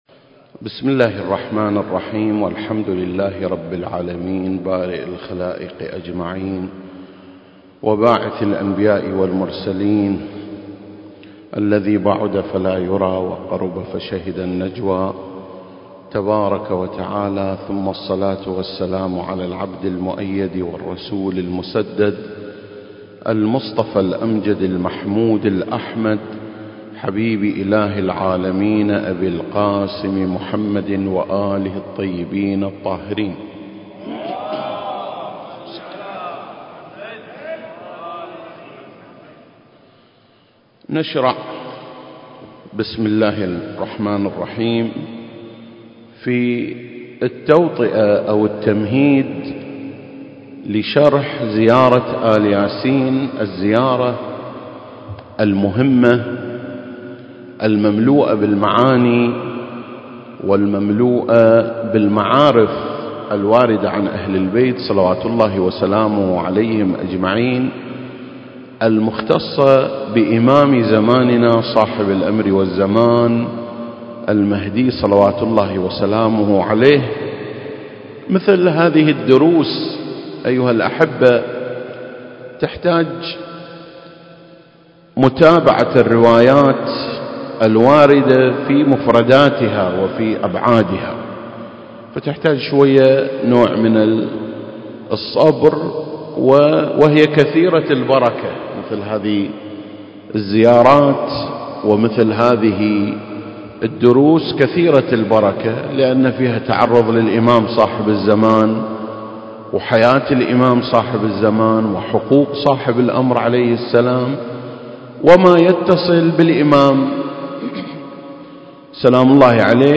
سلسلة: شرح زيارة آل ياسين (1) - تمهيد ومدخل المكان: مسجد مقامس - الكويت التاريخ: 2021